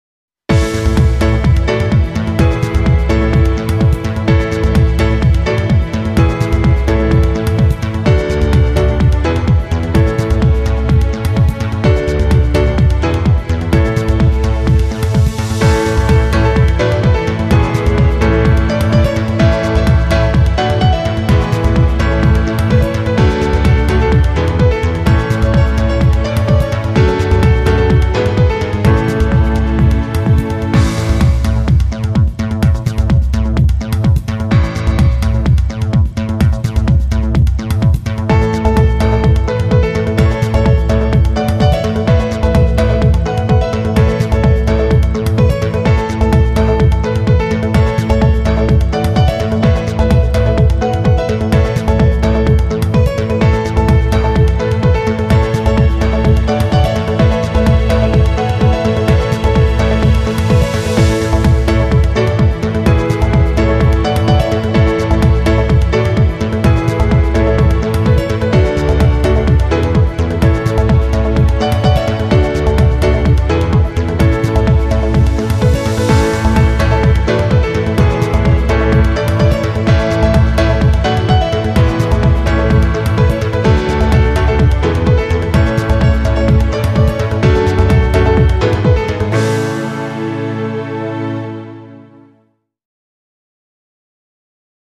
entrainant - concours - competition - victoire - generique